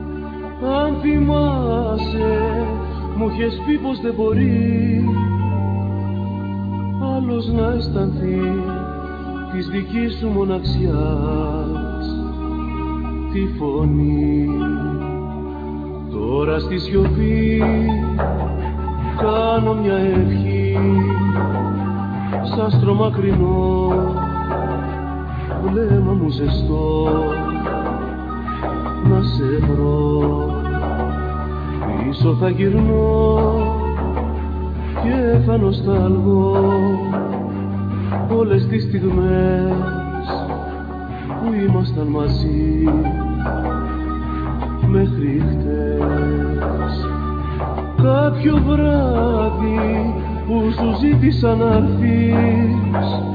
Kaval
Percussions
Keyboards
Drums
Guitar,Bass,Synthsizer programming
Vocals
North Indian flute